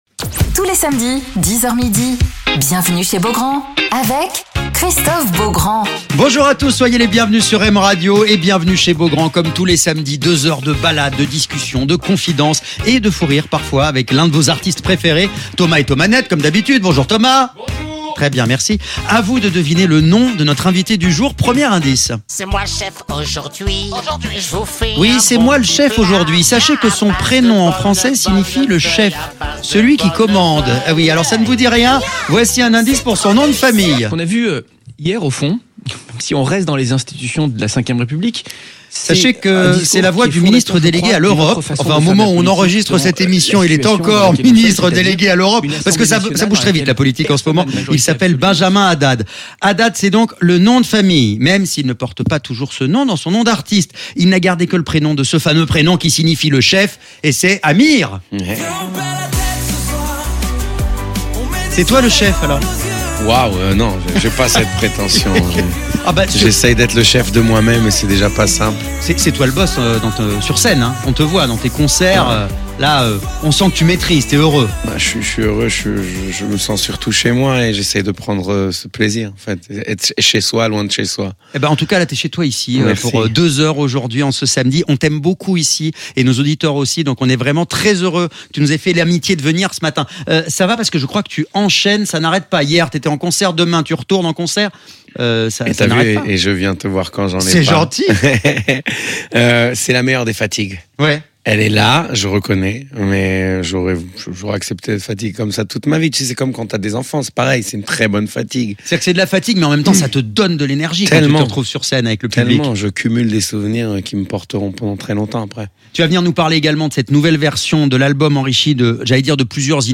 Alors qu'il sort la réédition de son album "C Amir²", Amir est l'invité de Christophe Beaugrand sur M Radio